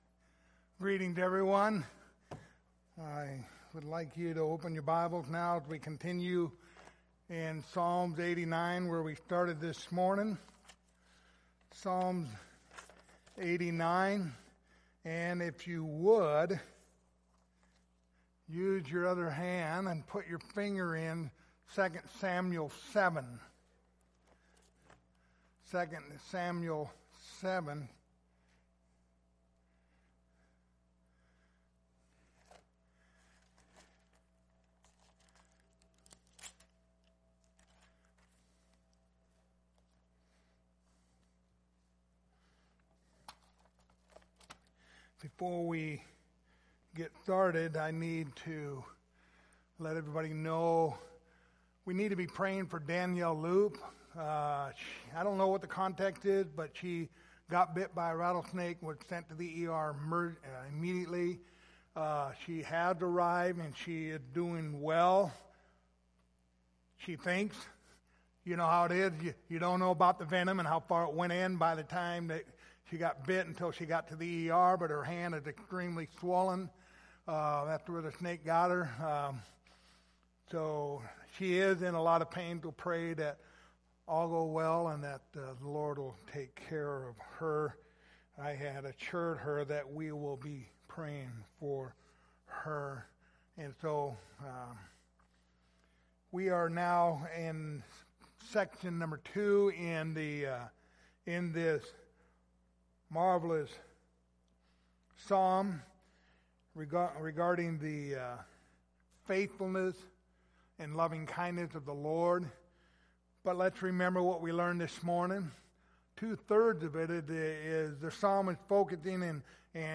Passage: Psalms 89:19-37 Service Type: Sunday Evening